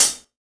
BR Hat Cl 3.WAV